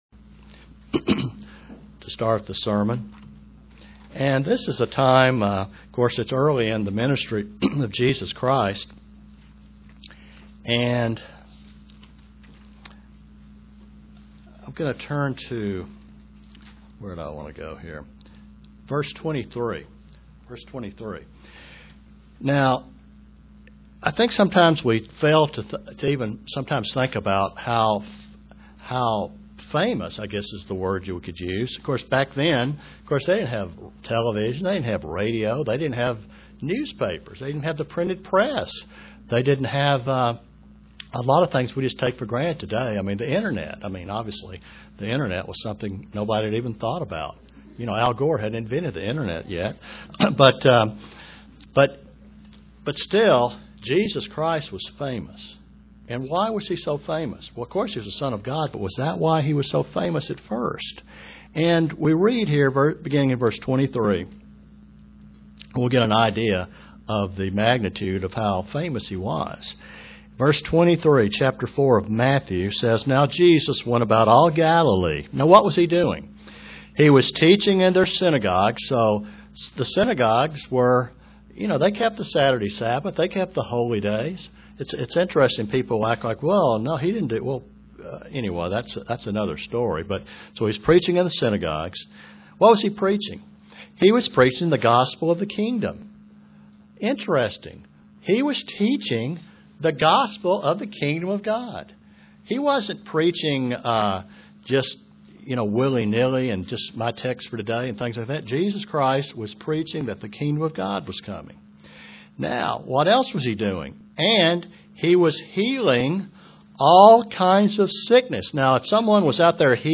Print Study of the beatitudes UCG Sermon Studying the bible?